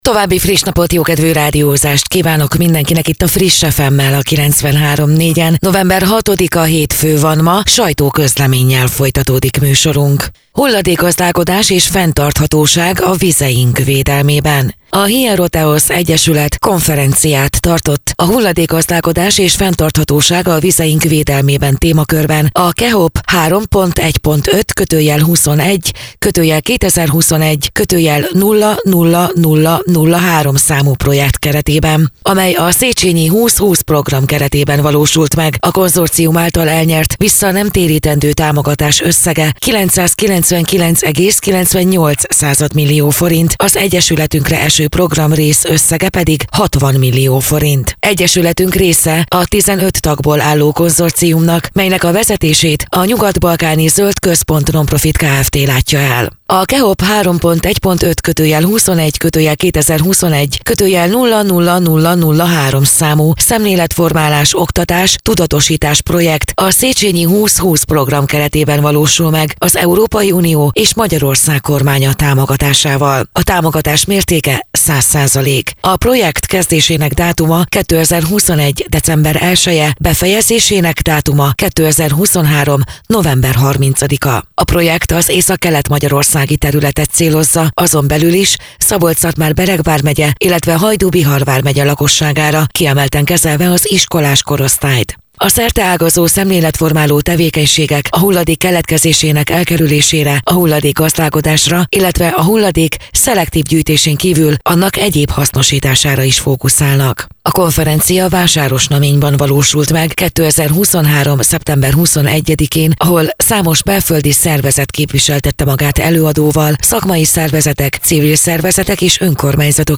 Rádió
2023.szeptember 21.: Vásárosnamény - Hulladékgazdálkodás és fenntarthatóság a vizeink védelmében - sajtóközlemény